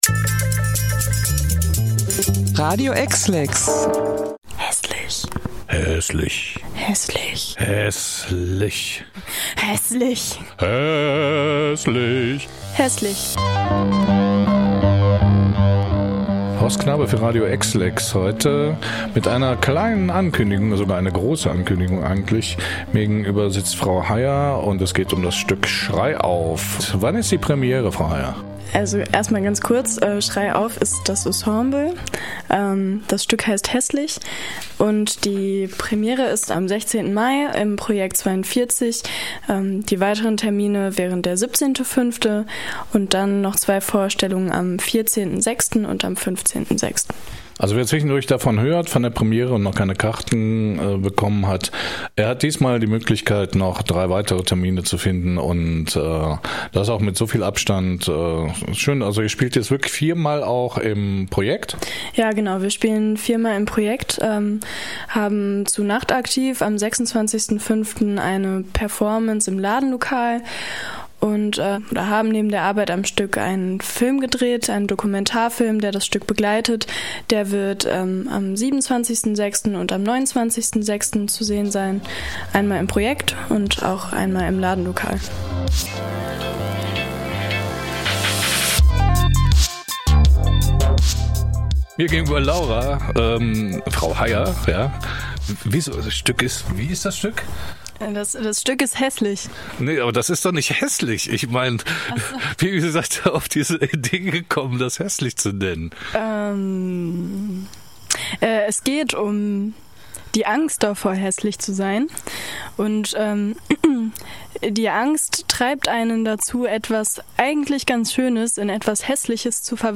Kulturreporter